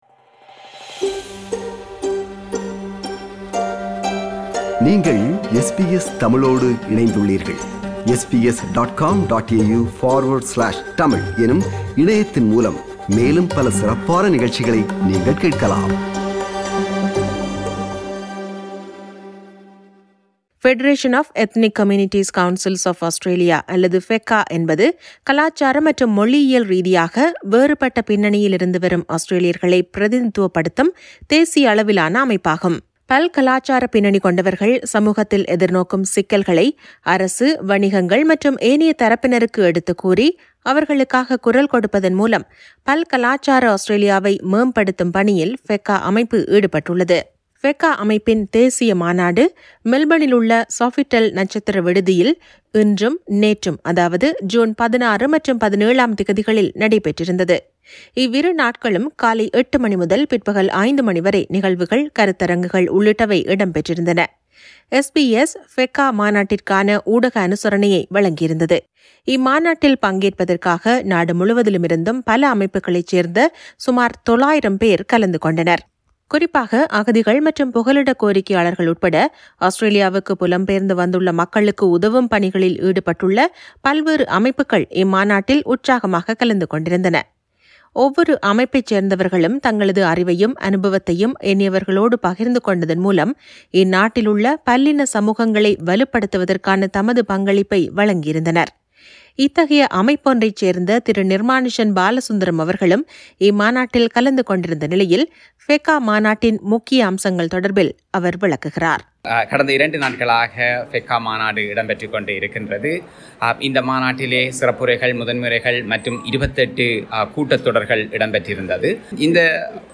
விவரணம் ஒன்றை முன்வைக்கிறார்.